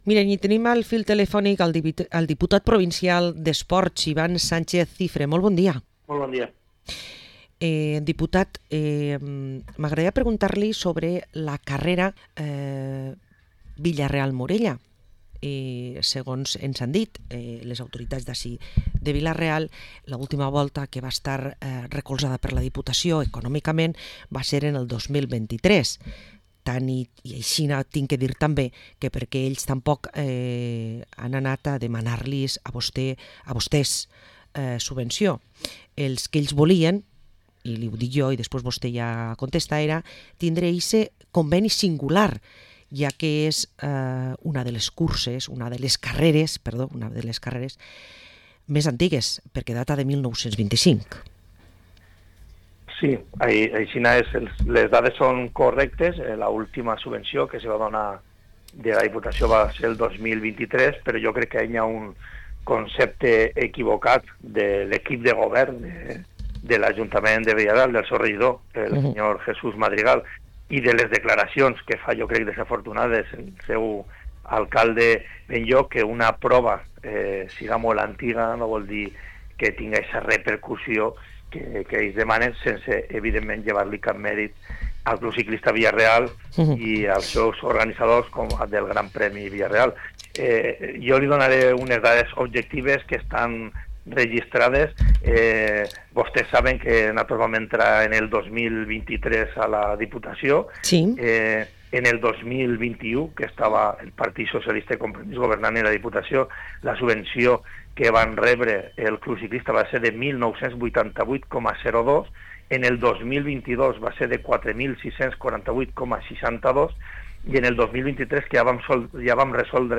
Parlem amb Iván Sánchez, diputat provincial d´Esports a Castelló